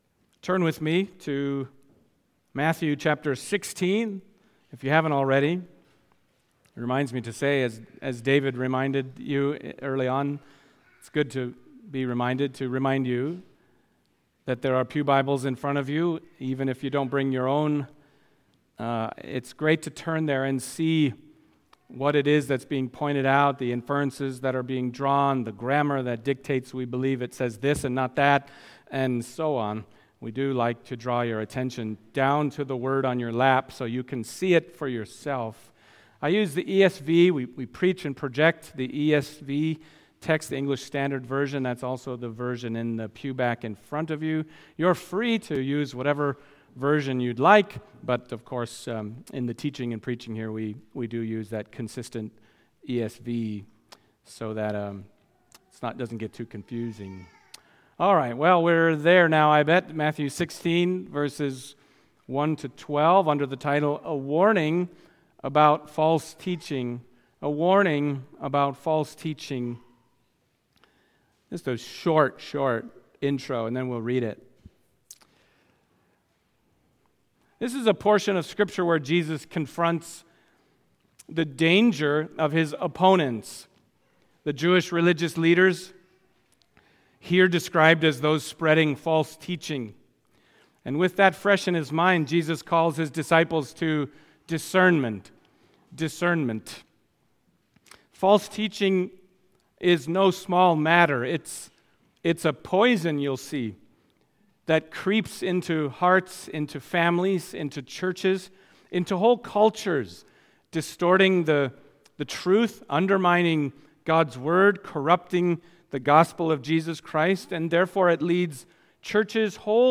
Matthew Passage: Matthew 16:1-12 Service Type: Sunday Morning Matthew 16:1-12 « The Bread of Life for the Nations “You Are the Christ” »